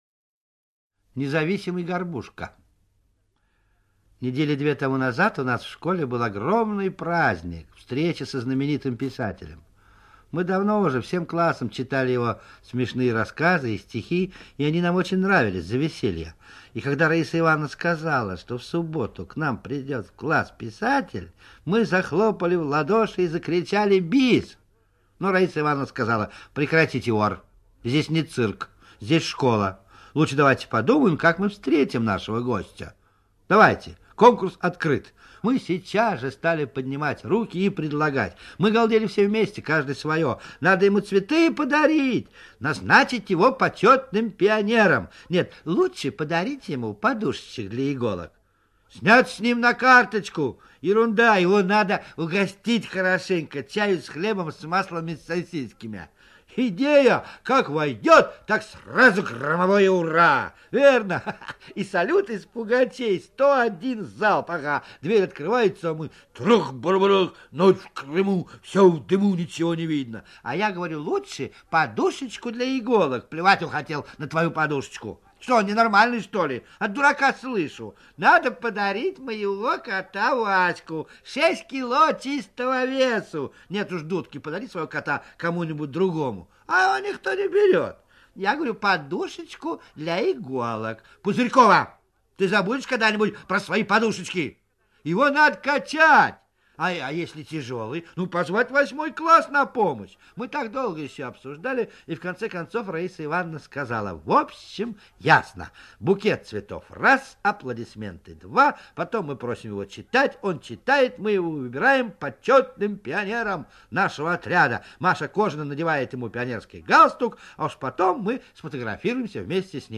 Слушайте Независимый Горбушка - аудио рассказ Драгунского В.Ю. Интересный и веселый рассказ про мальчика Дениску Кораблева и его друзей.